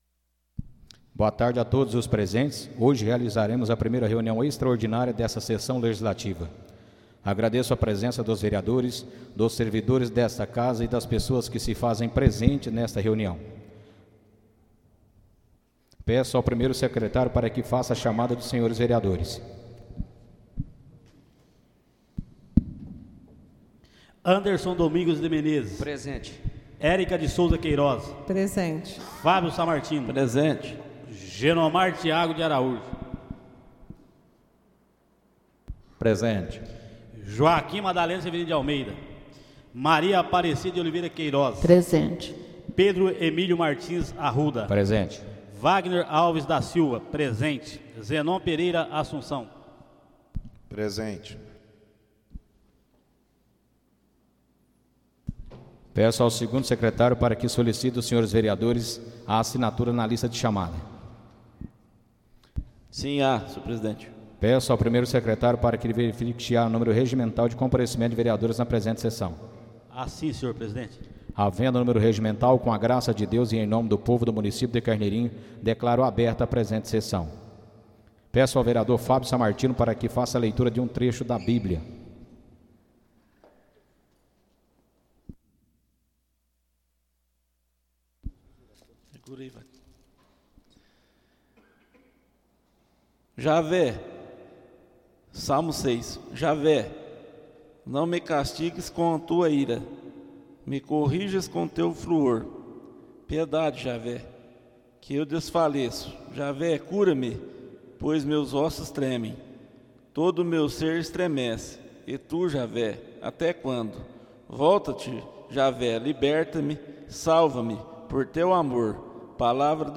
Áudio da 4.ª reunião extraordinária de 2024, realizada no dia 13 de Maio de 2024, na sala de sessões da Câmara Municipal de Carneirinho, Estado de Minas Gerais.